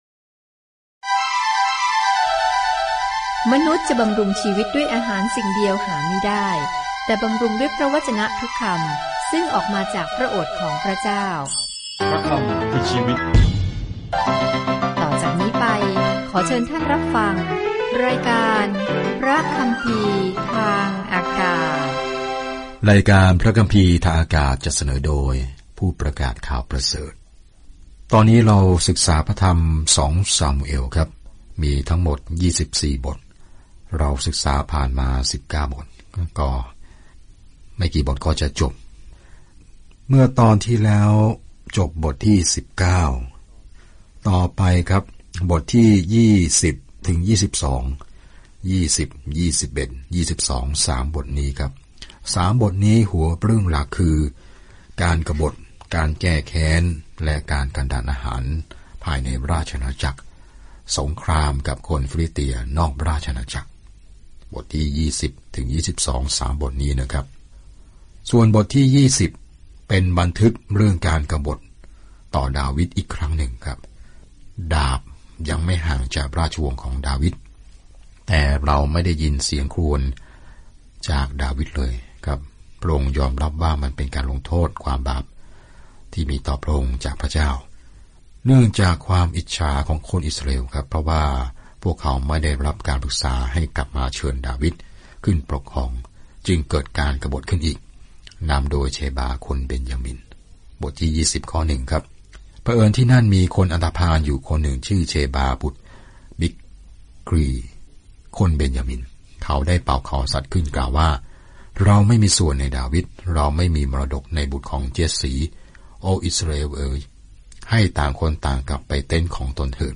เดินทางทุกวันผ่าน 2 ซามูเอลในขณะที่คุณฟังการศึกษาด้วยเสียงและอ่านข้อที่เลือกจากพระวจนะของพระเจ้า